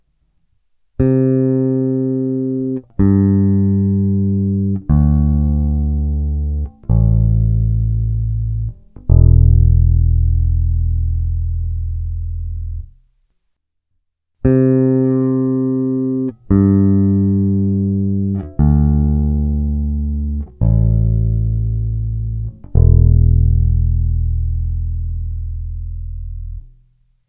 Teď jsem si udělal zkušební nahrávku s basou. Narval jsem měkkou špejli takhle aby na ní seděly struny.
nahrávku všech strun na pátém pražci. Co je se špejlí a co je bez?
Zvukově tipuju taky první špejle, ten rozdíl ve zvuku je dost znatelný.
Já jsem po těch letech už trochu hluchej, takže ten rozdíl mi nepřijde moc znatelný, možná je bez špejle (tedy s tvrdším nulákem) pocitově trochu zvonivější, ale asi se můžeme shodnout na tom, že rozdíl tam je.